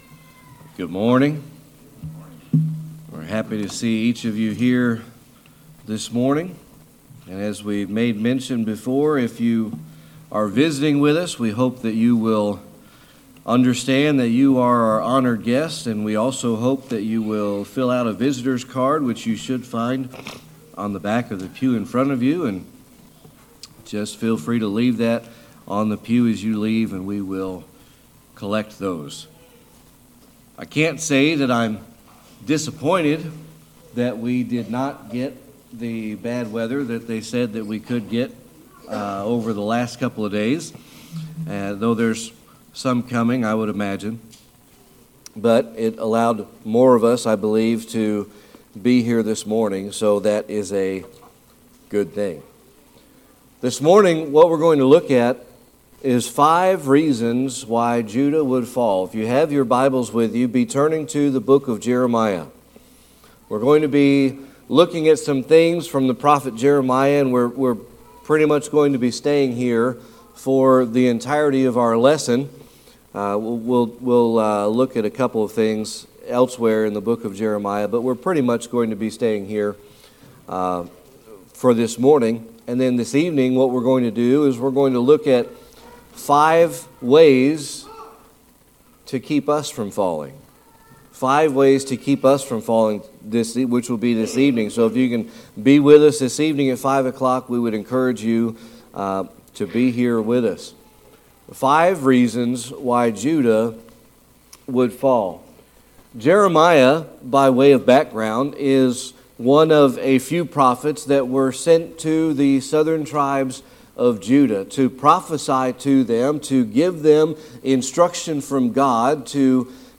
Discover the 5 reasons Judah would fall in this insightful sermon. Explore themes of backsliding, rejection of God's word, and the consequences of disobedience.
Jeremiah 8:4-12 Service Type: Sunday Morning Worship This morning